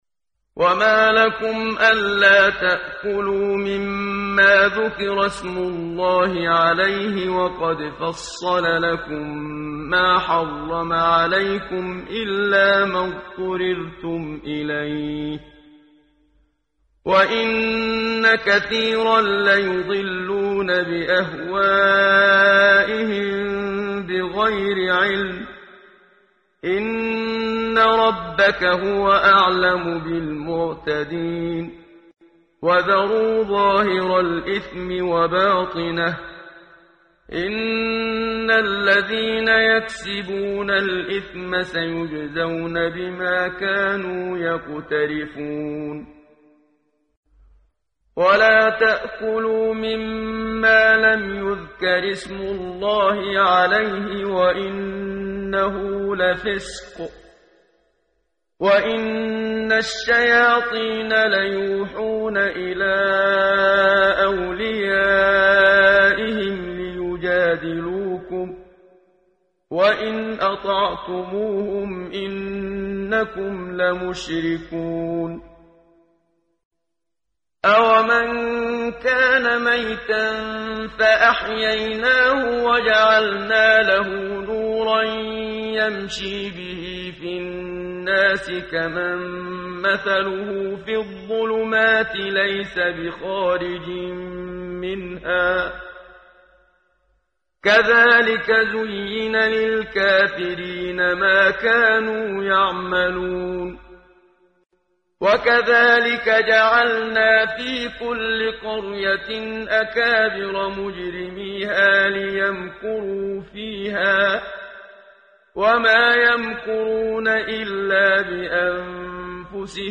ترتیل صفحه 143 سوره مبارکه انعام (جزء هشتم) از سری مجموعه صفحه ای از نور با صدای استاد محمد صدیق منشاوی